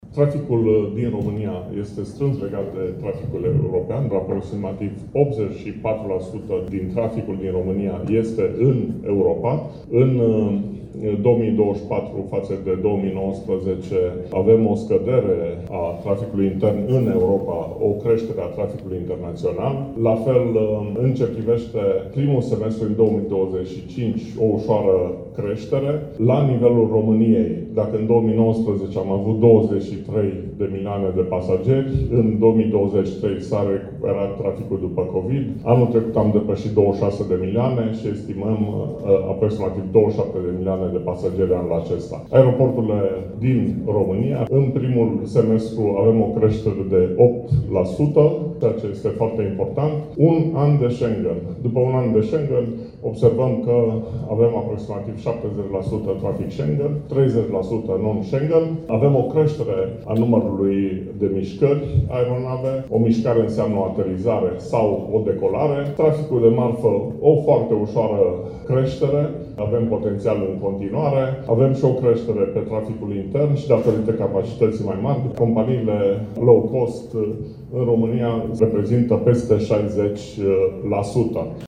a prezentat câteva date statistice la Conferința anuală a aeroporturilor din România, care se desfășoară în aceste zile pe litoral, în stațiunea Venus.